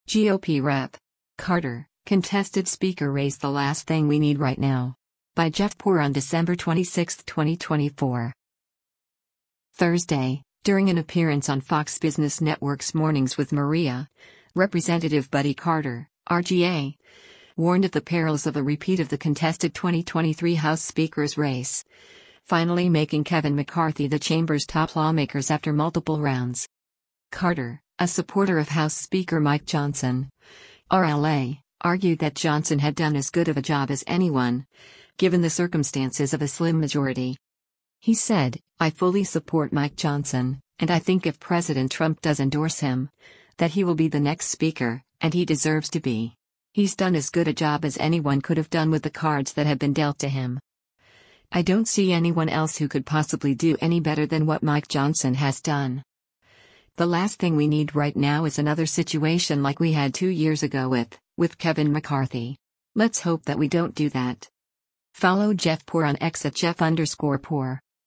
Thursday, during an appearance on Fox Business Network’s “Mornings with Maria,” Rep. Buddy Carter (R-GA) warned of the perils of a repeat of the contested 2023 House Speaker’s race, finally making Kevin McCarthy the chamber’s top lawmakers after multiple rounds.